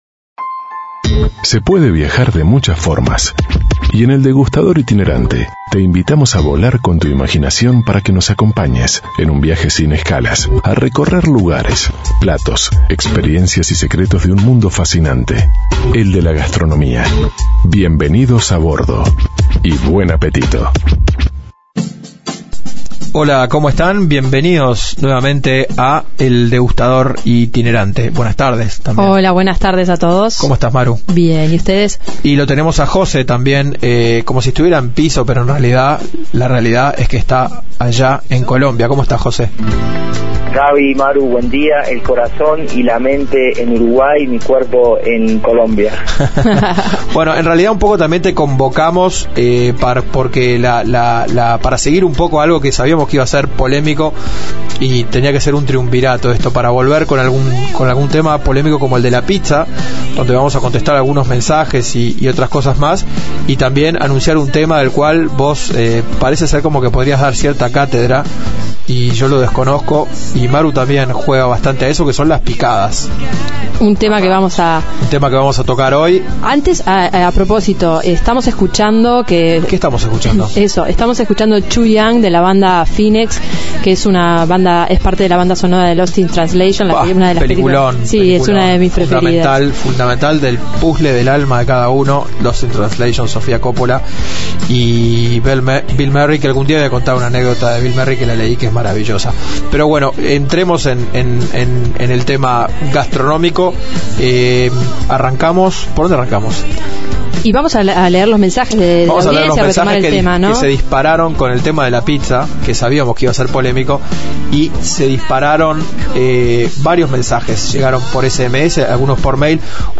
Este viernes, y a partir de la cantidad de mensajes que recibimos sobre el tema, seguimos debatiendo sobre la pizza, e iniciamos una tertulia sobre otro clásico uruguayo que también dará que hablar: las picadas. Además, conocemos los secretos de la cocina israelí a través de un típico desayuno local, la visita a una histórica panadería y el recorrido de un mercado popular en la ciudad de Tel Aviv en los que aromas, sonidos y sabores se dan la mano para redondear una experiencia única.